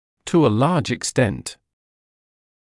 [tuː ə lɑːʤ ɪk’stent][туː э лаːдж ик’стэнт]в значительной степени